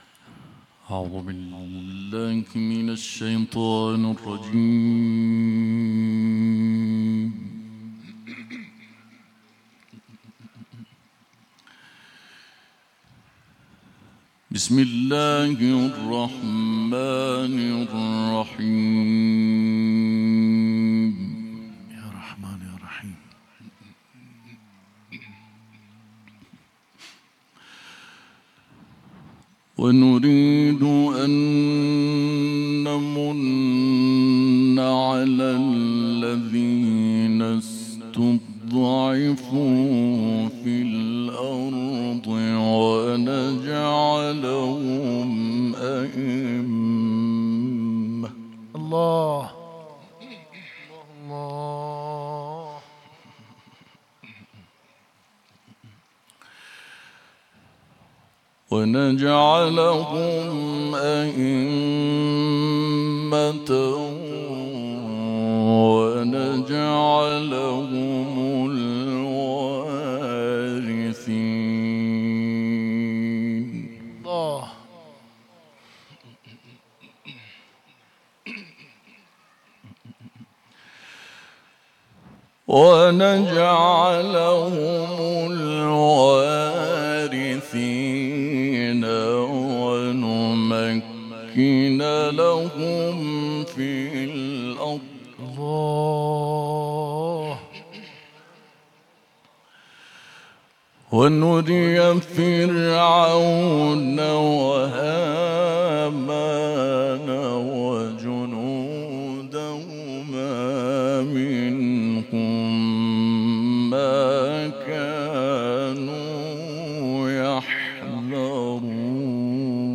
تلاوة عطرة مباركة بصوت القارئ الدولي